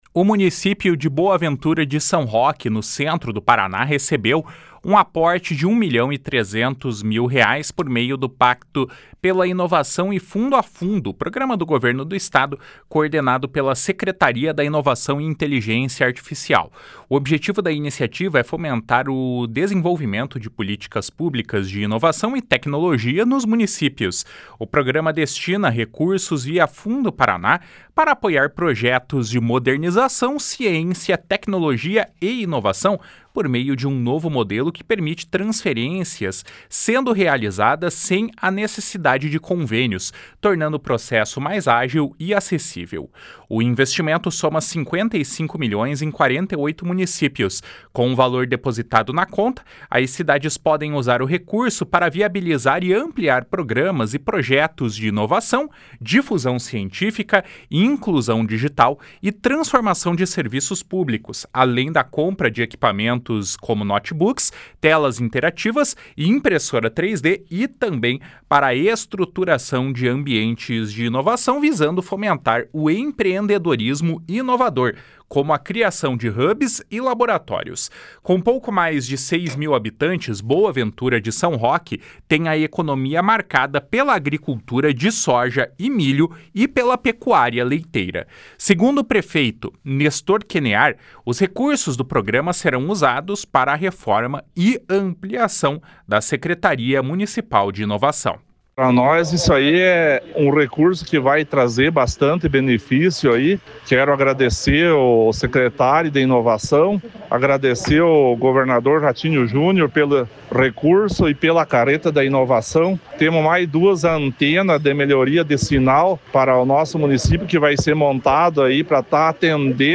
Segundo o prefeito Nestor Kenear, os recursos do programa serão usados para a reforma e ampliação da Secretaria Municipal de Inovação. // SONORA NESTOR KENEAR //